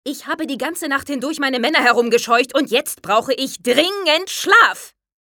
vertont Captain Morgane, die wohl emanzipierteste Piratenkapitänin überhaupt